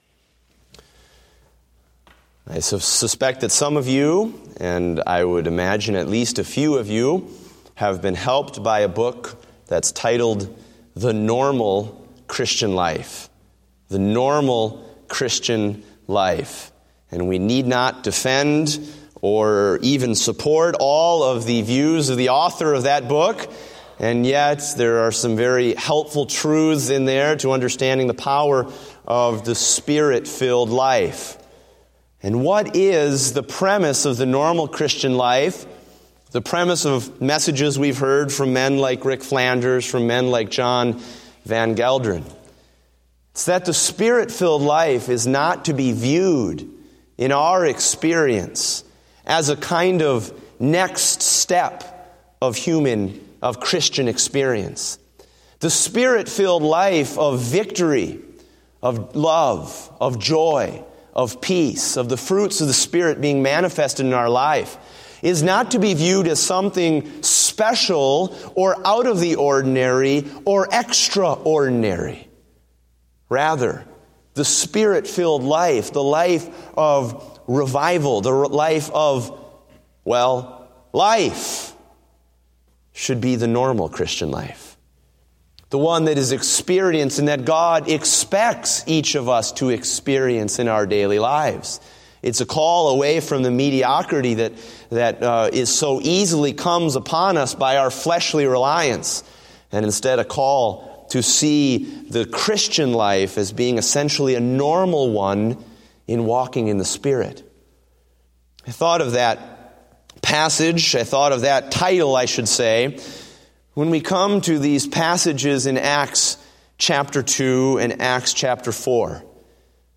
Date: December 27, 2015 (Evening Service)